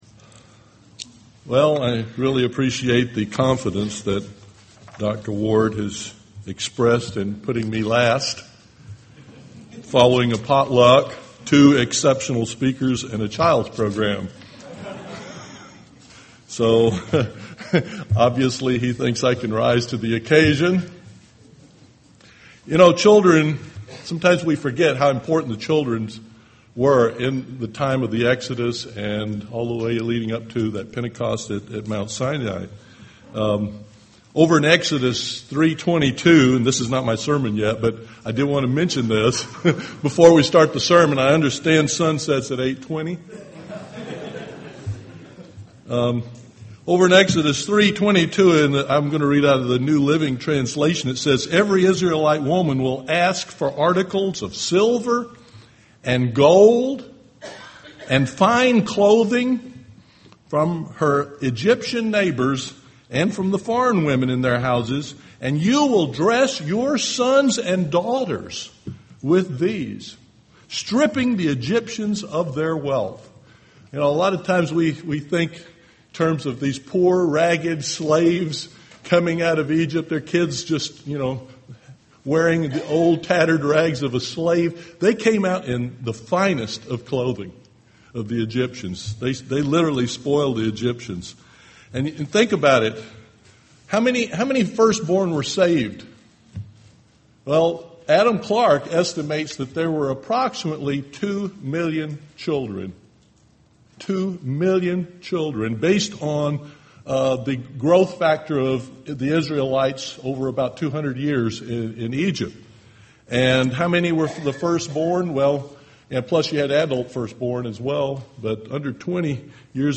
Given in East Texas
UCG Sermon Studying the bible?